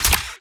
Plasma Rifle
GUNMech_Reload_04_SFRMS_SCIWPNS.wav